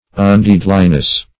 undeadliness - definition of undeadliness - synonyms, pronunciation, spelling from Free Dictionary
-- Un*dead"li*ness , n. [Obs.]